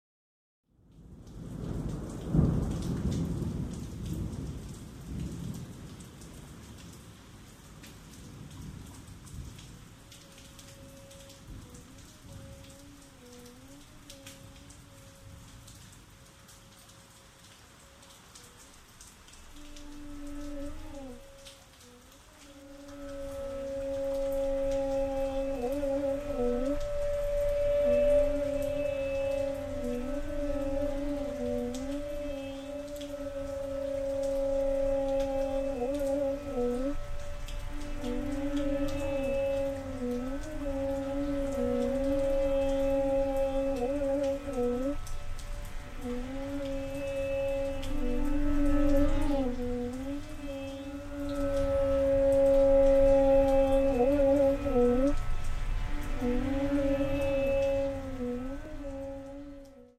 Voice, circuit bent flower
Keyboards, Electronics
soundscapes from India